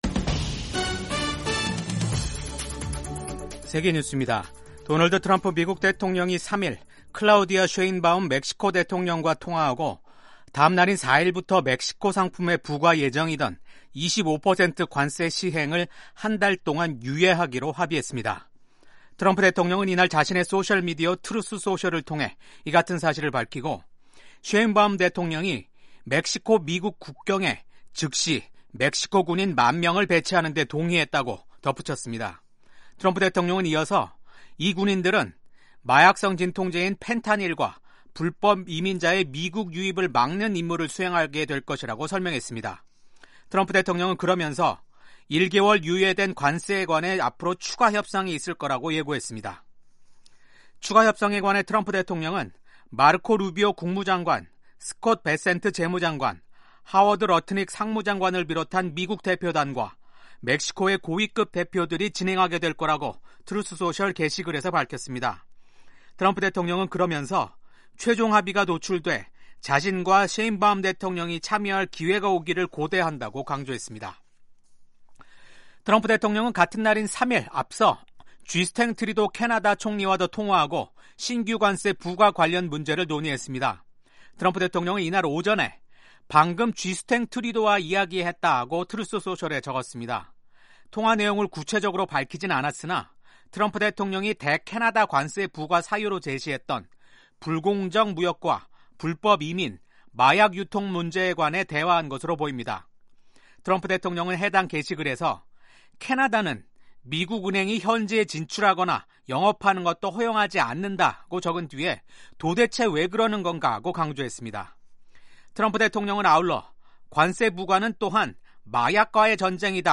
생방송 여기는 워싱턴입니다 2025/2/4 아침